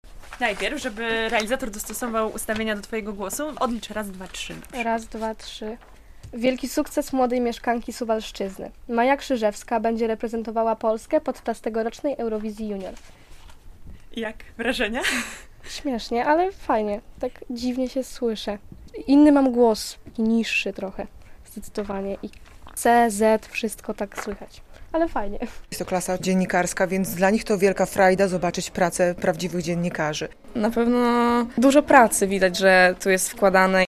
Dzień otwarty - relacja